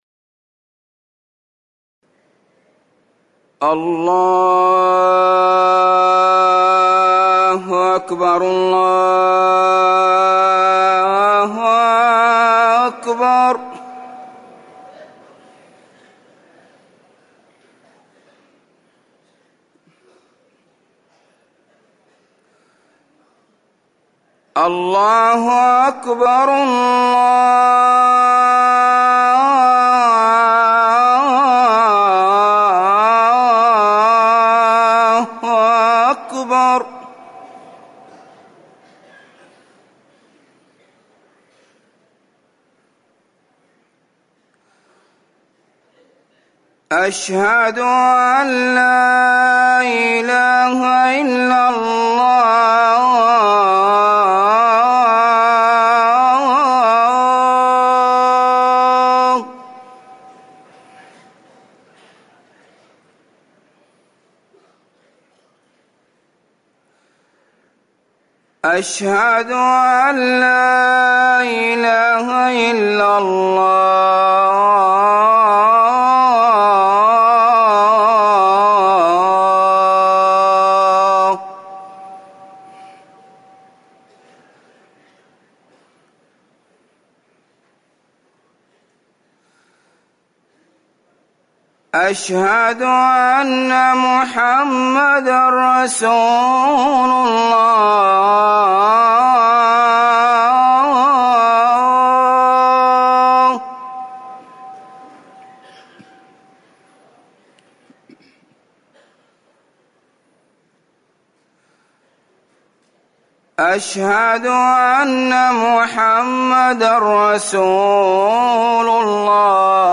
أذان الفجر الأول
تاريخ النشر ١٢ محرم ١٤٤١ هـ المكان: المسجد النبوي الشيخ